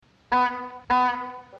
8. Смешные гудки Вуди Вудпекера